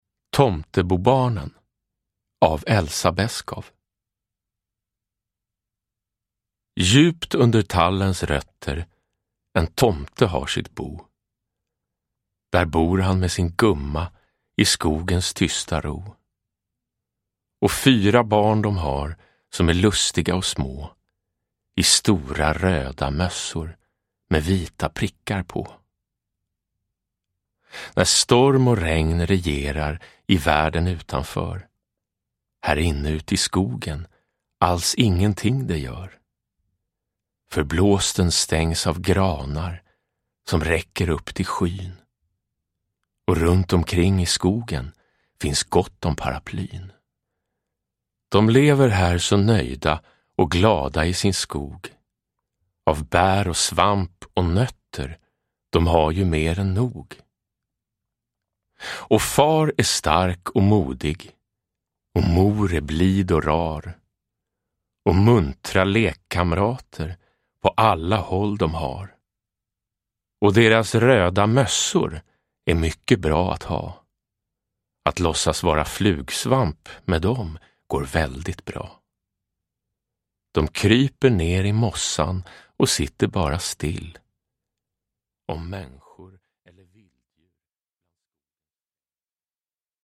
Tomtebobarnen – Ljudbok
Uppläsare: Jonas Karlsson